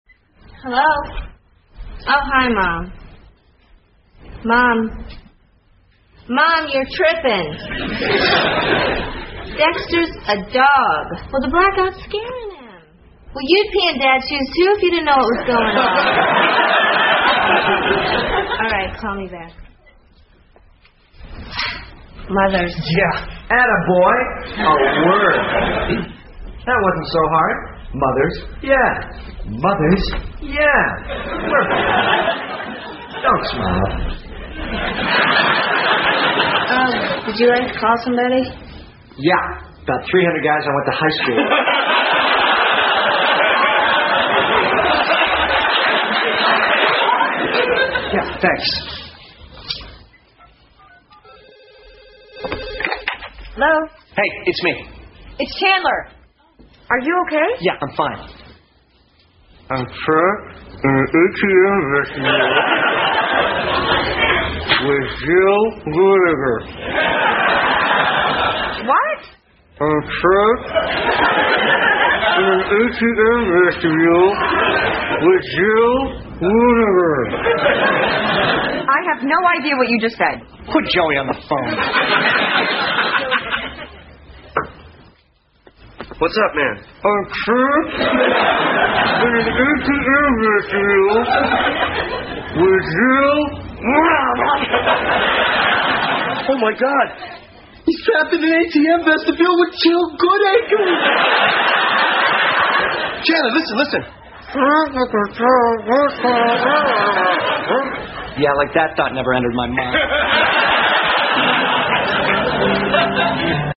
在线英语听力室老友记精校版第1季 第76期:停电(3)的听力文件下载, 《老友记精校版》是美国乃至全世界最受欢迎的情景喜剧，一共拍摄了10季，以其幽默的对白和与现实生活的贴近吸引了无数的观众，精校版栏目搭配高音质音频与同步双语字幕，是练习提升英语听力水平，积累英语知识的好帮手。